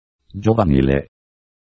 I made the soundfiles with the text-to-speech program at: